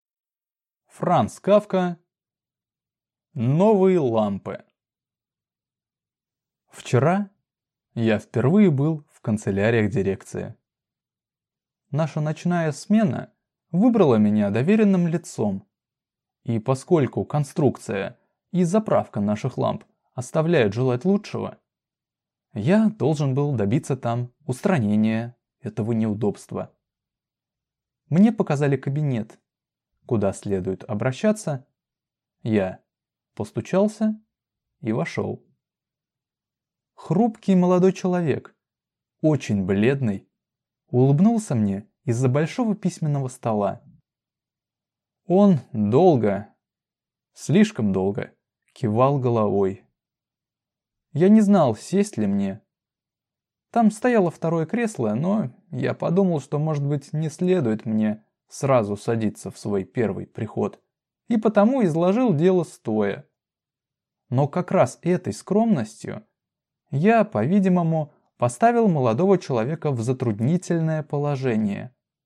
Аудиокнига Новые лампы | Библиотека аудиокниг